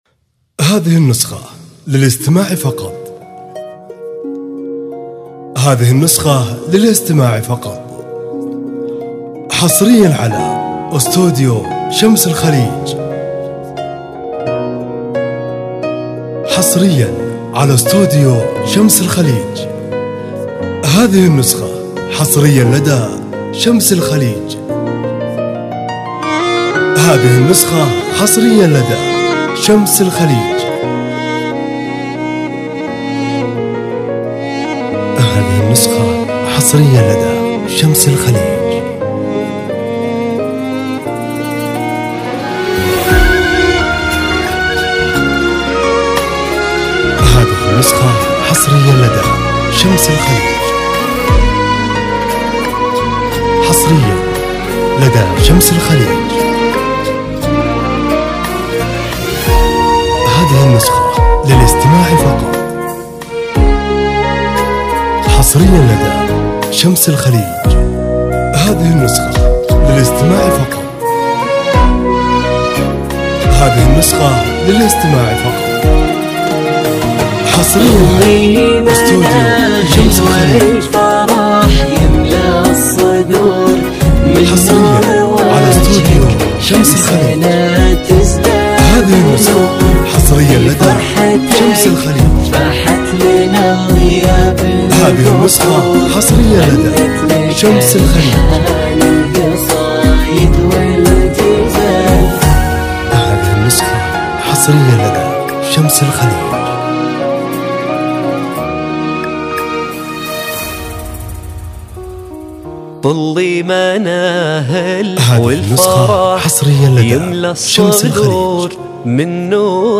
زفة مسار موسيقية
من اجمل الزفات المناسبة لدخول العروسين بأجواء راقية.
• نوع الزفة: زفة مسار عروس
زفة مسار موسيقى